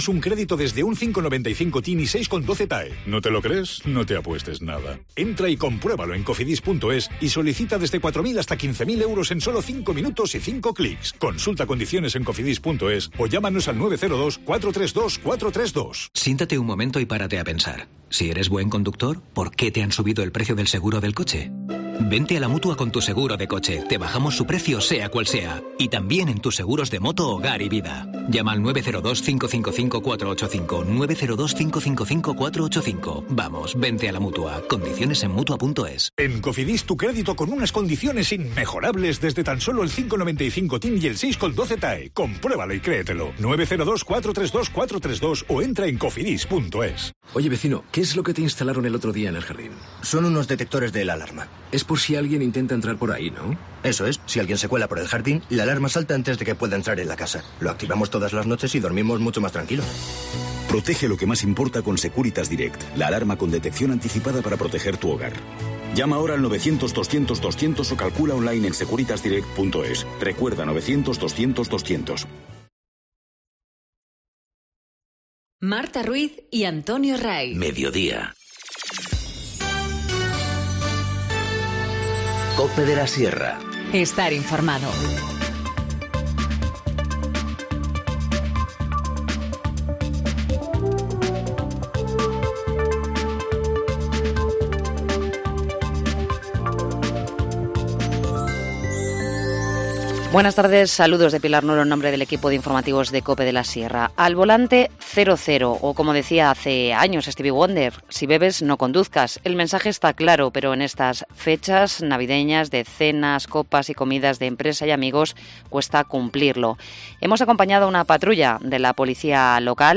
Informativo Mediodía 13 dic- 14:20h